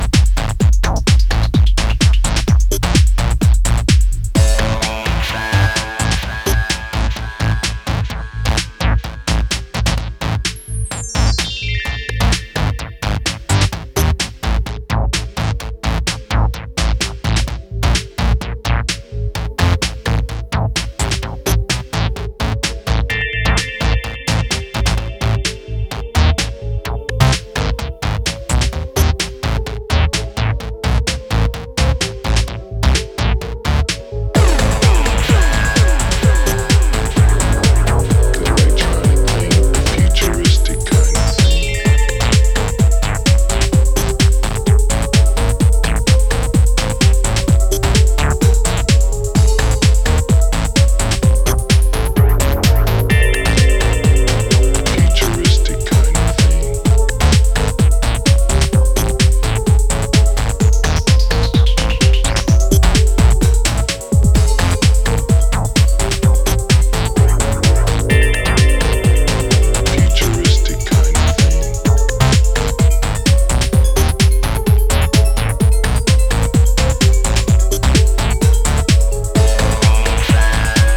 the e.p is full of timeless, heavy, dancefloor rhythms.